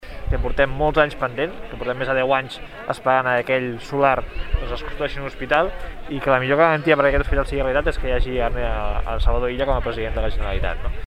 Declaracions de Pol Gibert. Hospital: